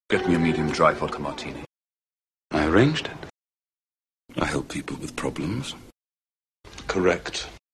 Dalton does also use the approximant [ɹ], the standard r in England, America and elsewhere; and it seems to be his preferred realization after t and d:
dalton_approximant.mp3